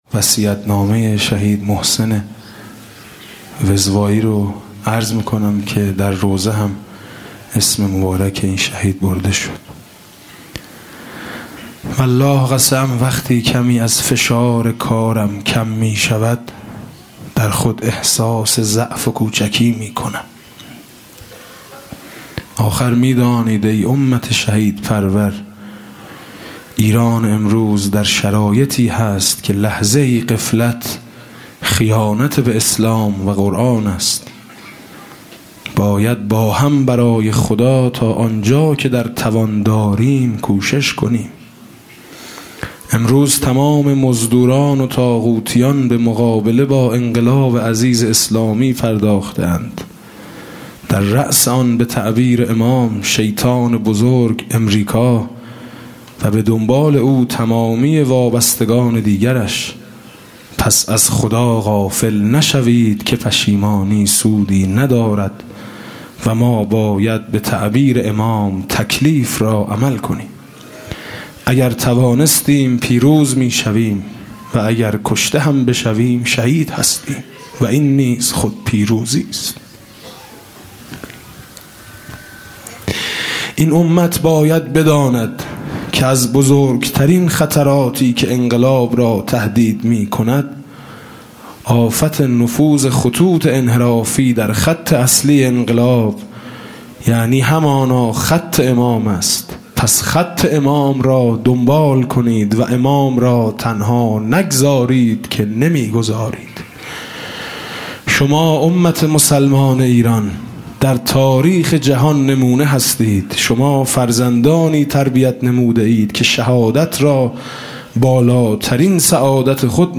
شب اول محرم 96 - هیئت میثاق - فرازی از وصیت نامه محسن وزوایی